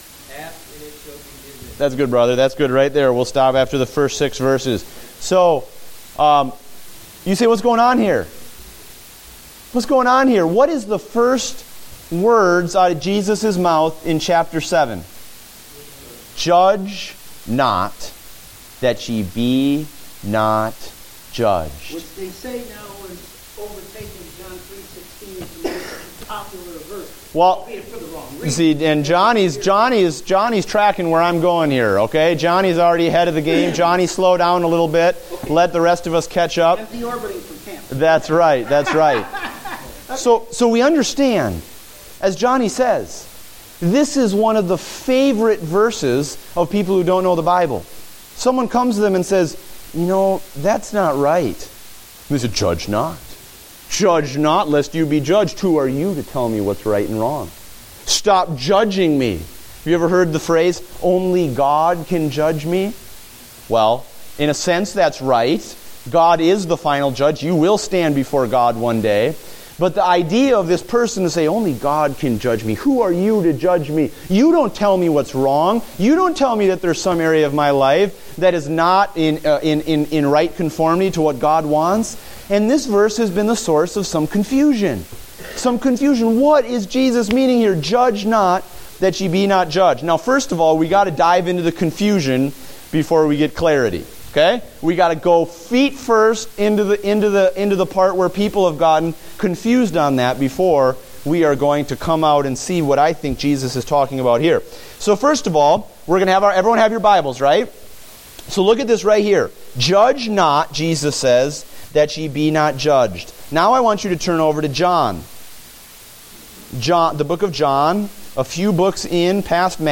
Date: August 30, 2015 (Adult Sunday School)